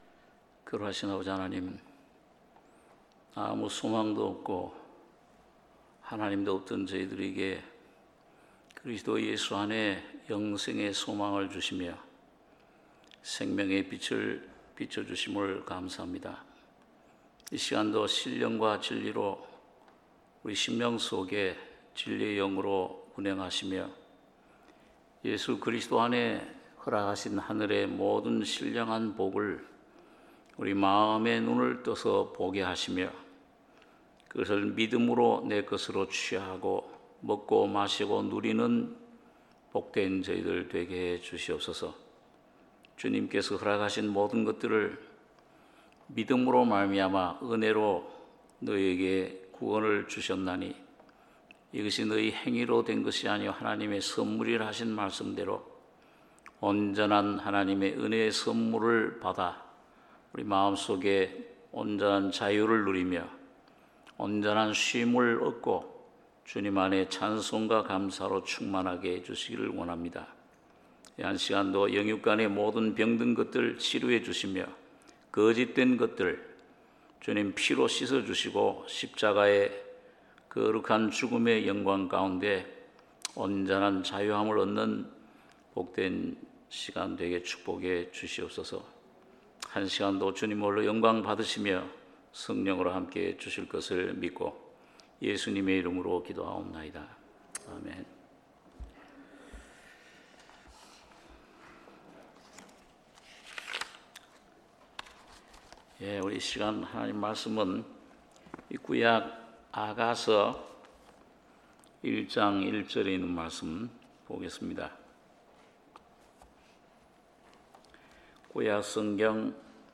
수요예배 아가 1장 1~8절